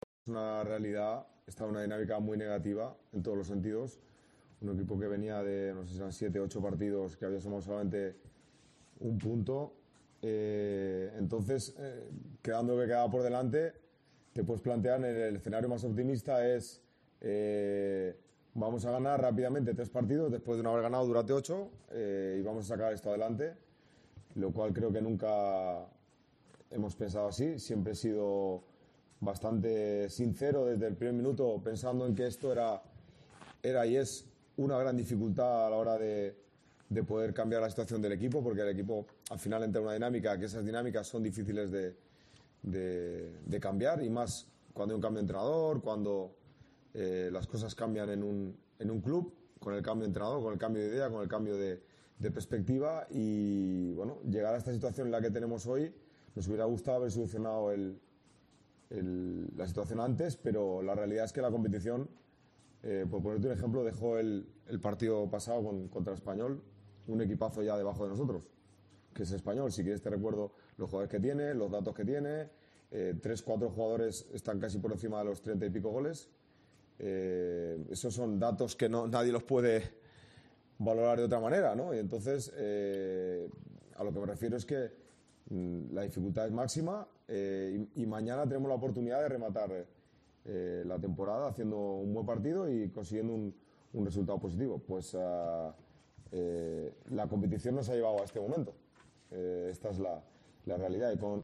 “No me planteo nada más que no sea el partido del Betis. Es el último partido, no nos podemos volver locos. Estamos en el último kilómetro de este maratón tan difícil y tenemos que rematar con contundencia, con determinación. Tenemos que afrontarlo como un partido muy importante pero también sabiendo que tenemos opciones”, explicó en una rueda de prensa.